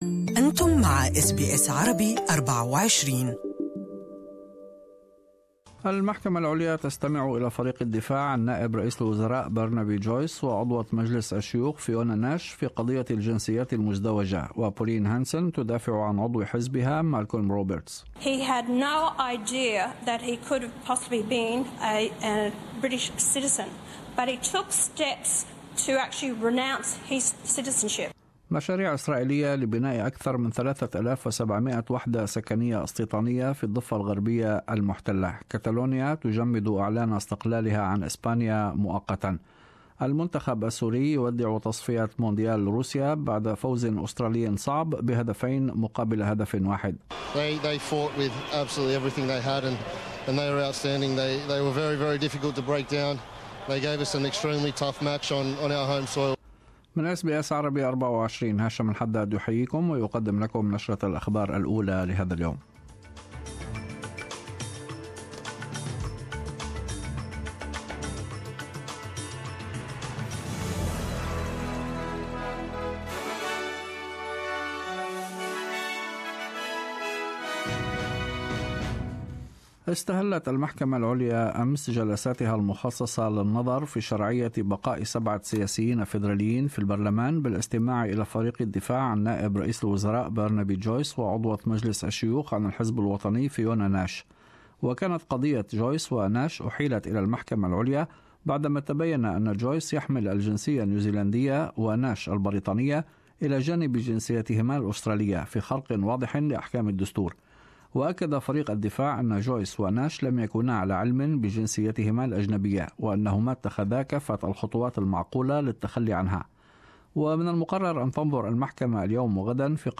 In this bulletin ... ** Catalonia's government declares the region has earned the right to independence ** Wildfires rage across California and ** The Socceroos defeat Syria to proceed to the next stage of World Cup qualifications